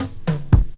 10DR.BREAK.wav